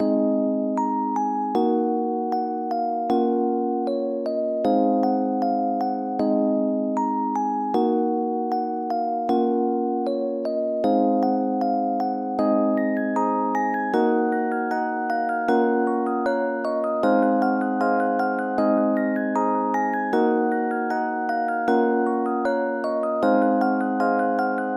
Tag: 155 bpm Trap Loops Bells Loops 4.17 MB wav Key : G